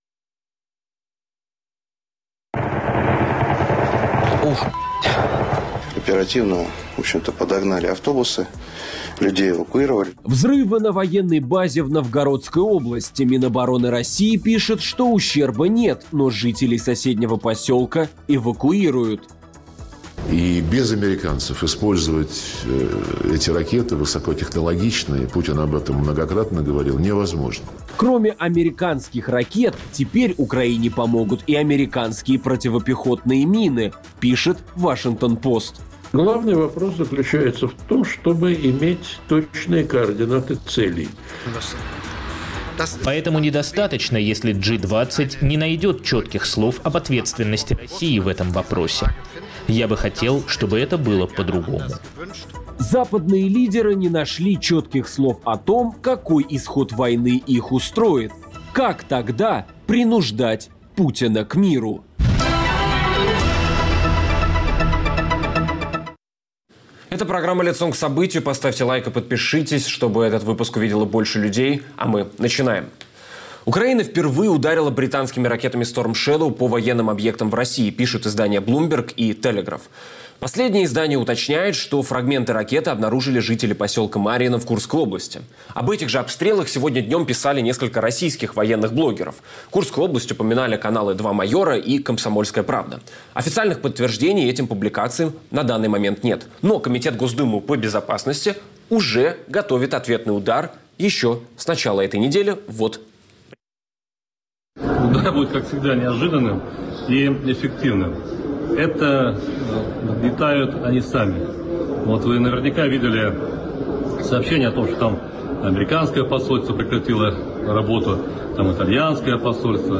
В ежедневном режиме анализируем главные события дня. Все детали в прямом эфире, всегда Лицом к Событию